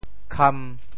How to say the words 戡 in Teochew？
戡 Radical and Phonetic Radical 戈 Total Number of Strokes 13 Number of Strokes 9 Mandarin Reading kān TeoChew Phonetic TeoThew kam1 文 Chinese Definitions 戡〈动〉 (形声。